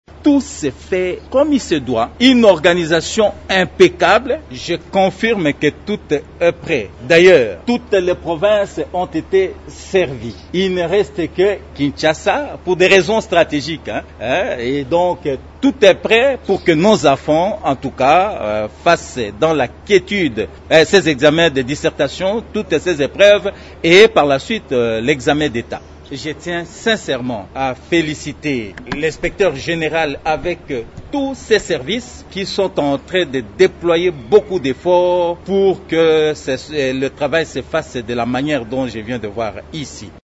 Il l’a dit en marge de la visite effectuée le même samedi à l’Imprimerie de l’Inspection générale de l’EPSP.